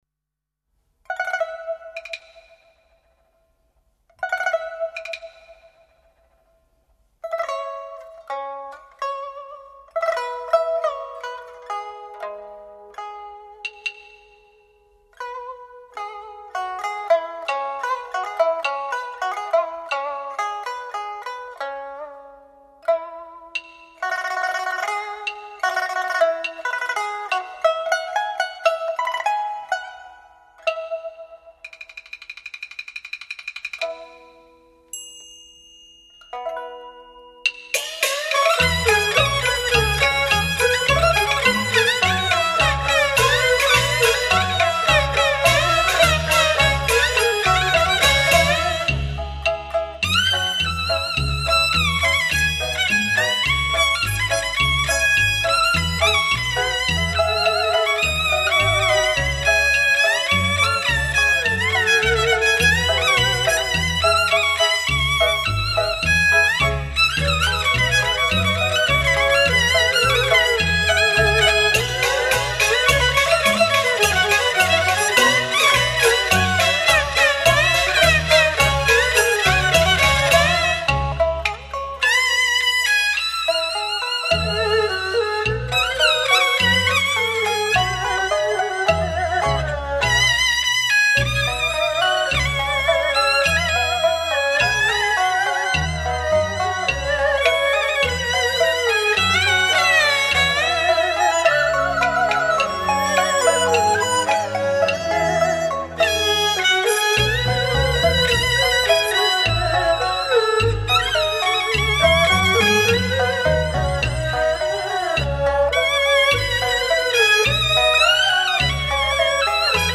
专辑类型：纯戏曲音乐